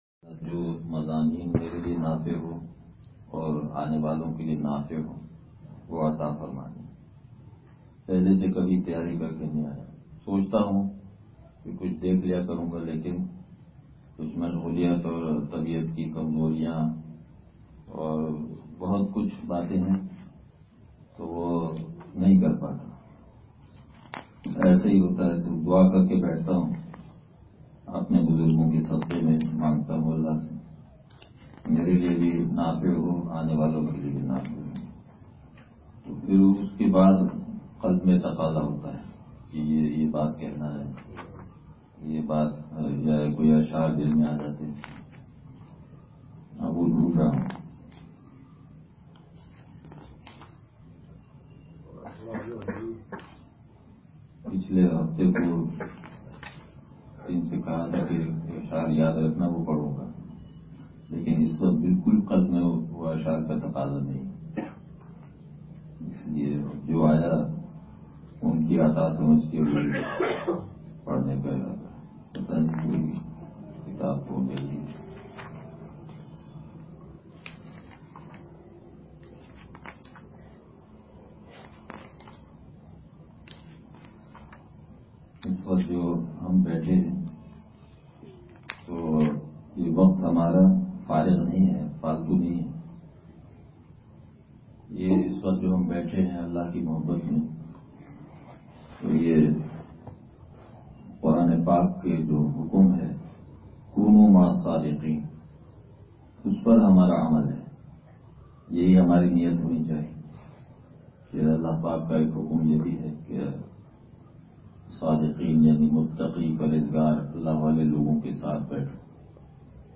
بیان – لانڈھی